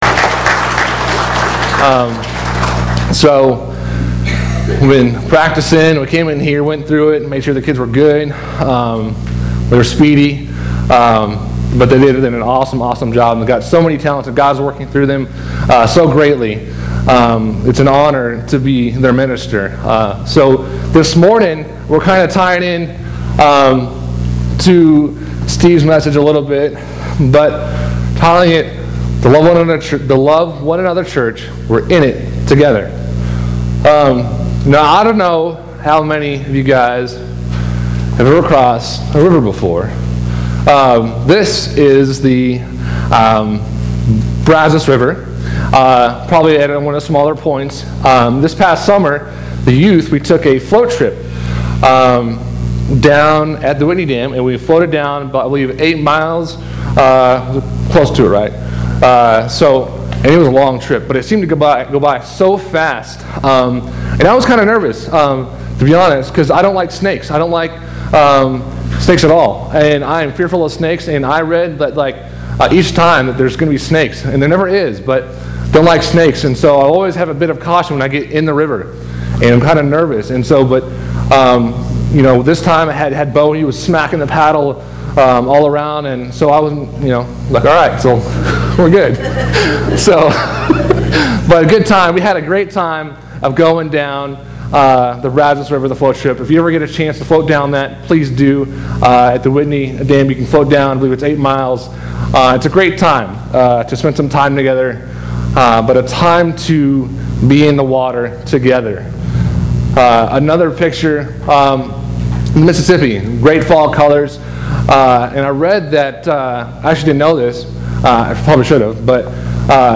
Sermons - Clifton Church of Christ
Youth Sunday